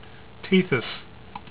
"TEE this" ) is the ninth of Saturn's known satellites: